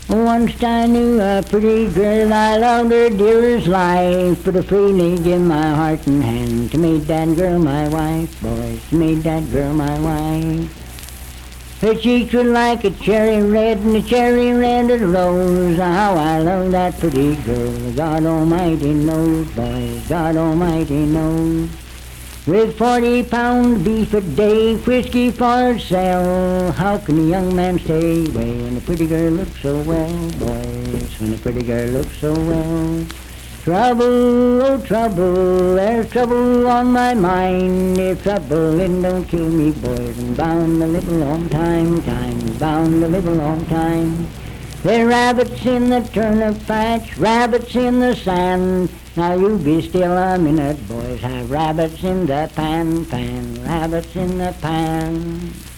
Unaccompanied vocal music
Verse-refrain 5(5w/R).
Performed in Sandyville, Jackson County, WV.
Voice (sung)